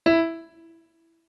MIDI-Synthesizer/Project/Piano/44.ogg at 51c16a17ac42a0203ee77c8c68e83996ce3f6132